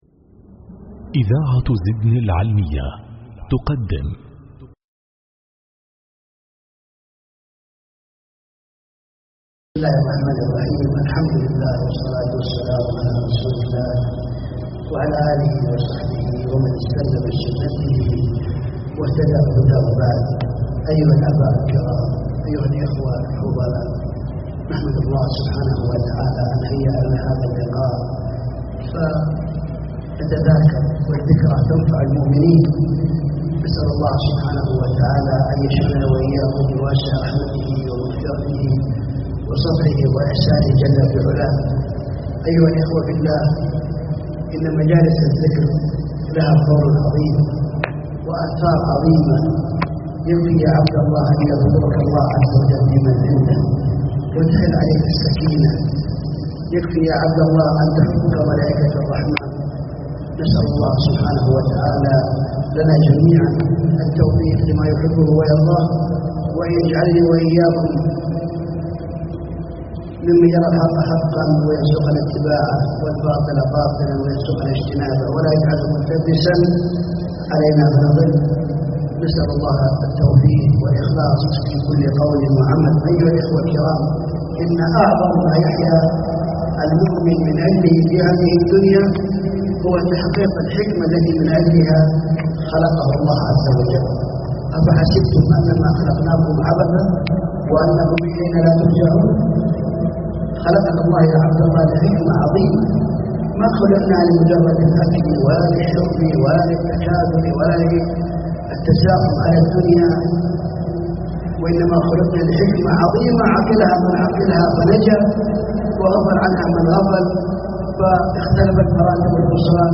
محاضرة
مسجد سهام العلي بمحلية جازان